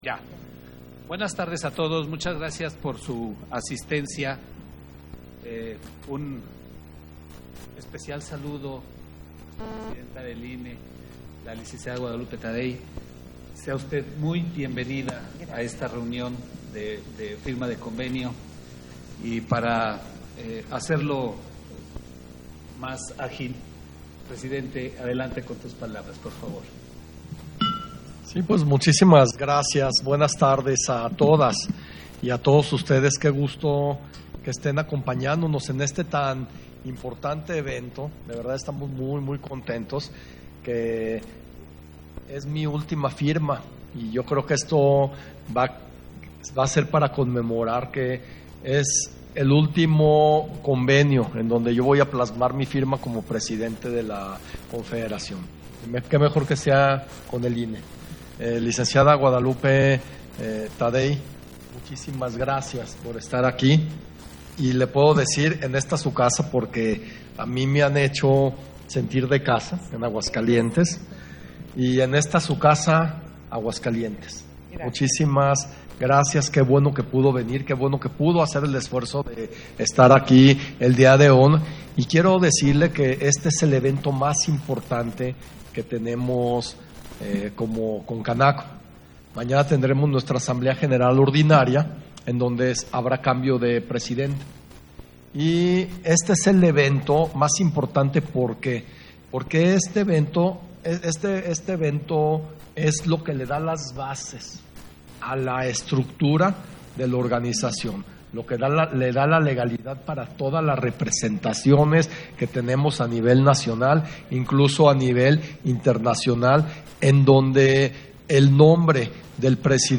Audio de la firma de convenio de colaboración INE-CONCANACO SERVYTUR